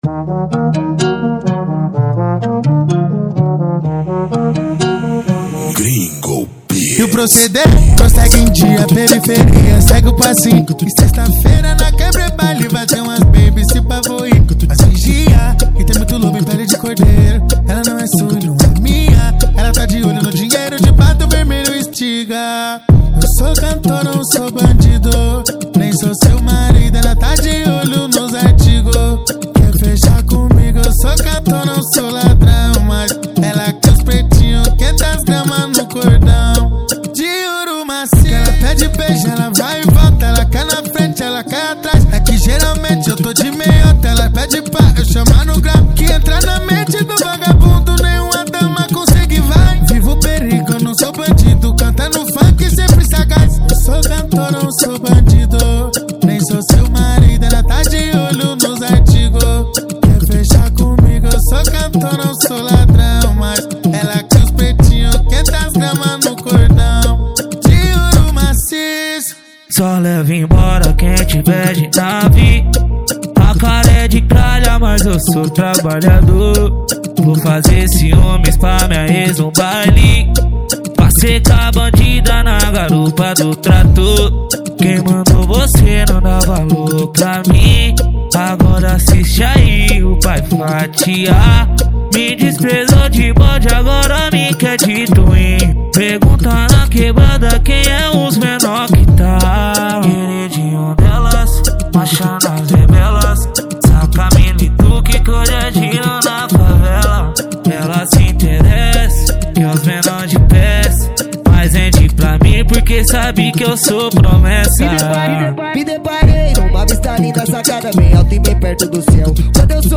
Gênero: MPB